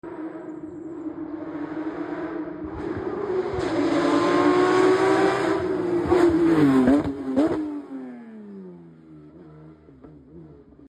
Un passaggio della Porsche 992 sound effects free download
74^ Trento Bondone 2025